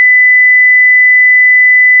kap140-disengage.wav